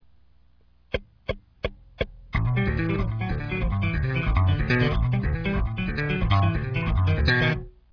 Download RealAudio (faster tempo)
ex3fast.rm